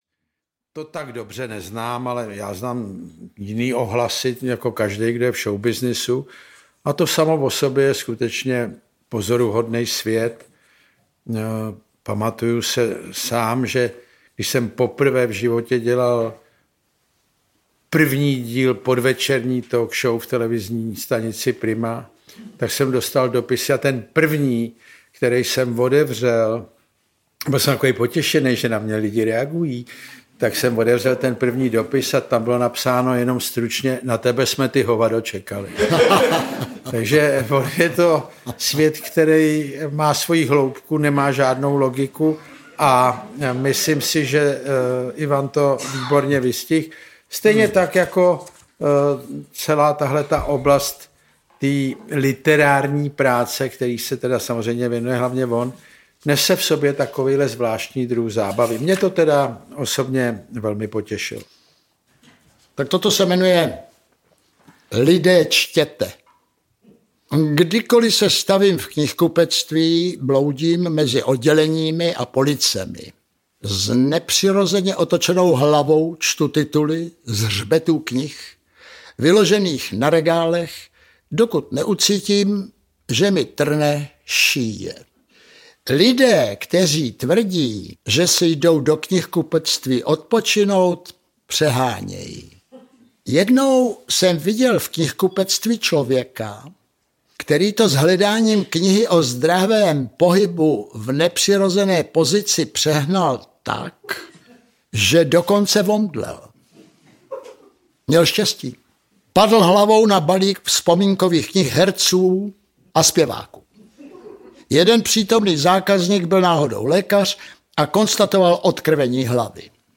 Ukázka z knihy
Záznam představení z pražské Violy.
• InterpretJan Kraus, Ivan Kraus